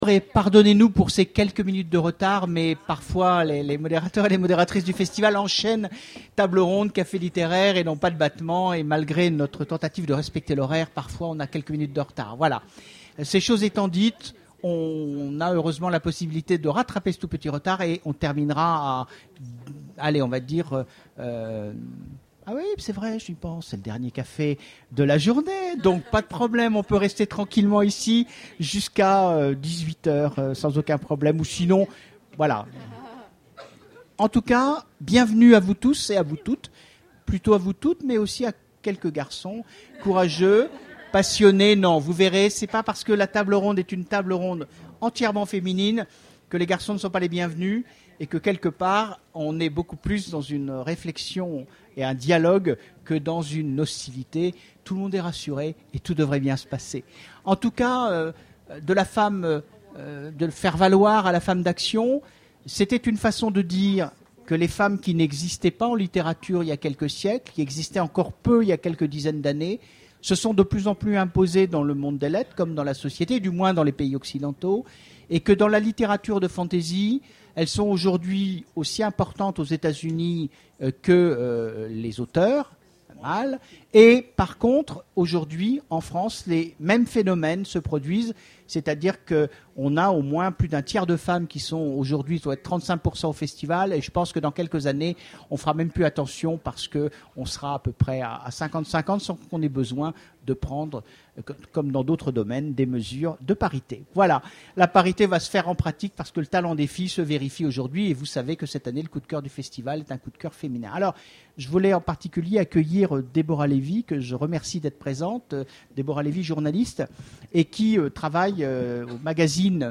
Imaginales 2013 : Conférence Femmes et fantasy...